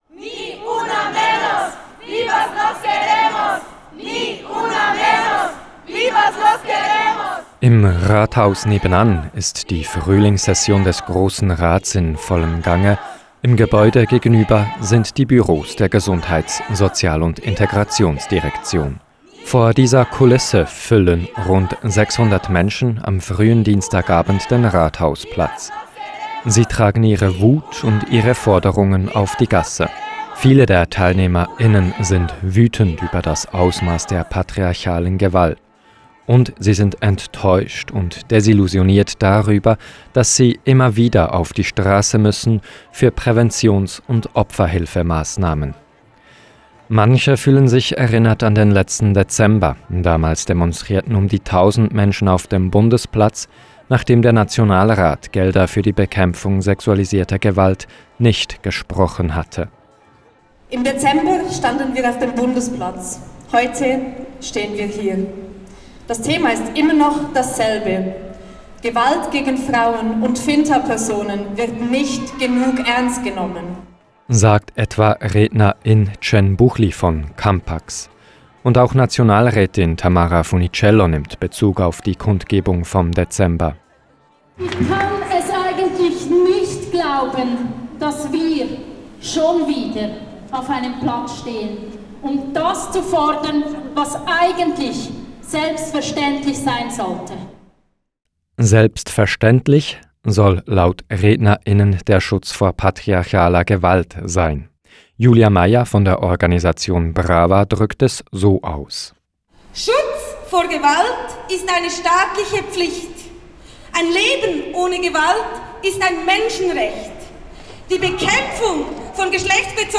Die spanische Parole «Ni Una Menos» – zu deutsch «nicht eine weniger» – hallt auf dem Rathausplatz wider.
Vor dieser Kulisse füllen rund 600 Menschen am frühen Dienstagabend den Rathausplatz.